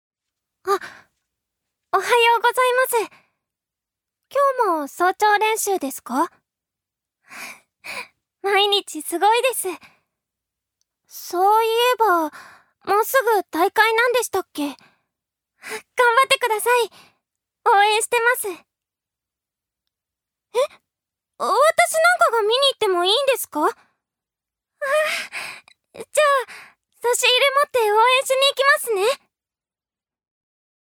ジュニア：女性
セリフ１